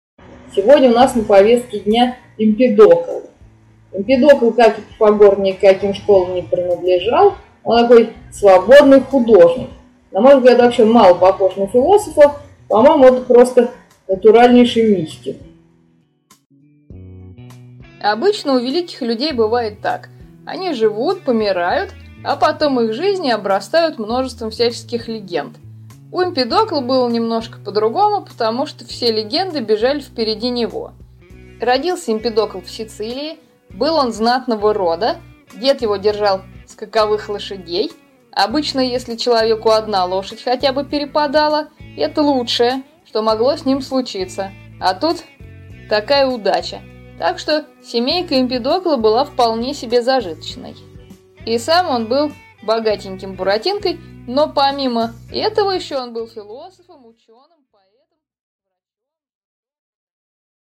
Аудиокнига 5. Древнегреческие философы. Эмпедокл | Библиотека аудиокниг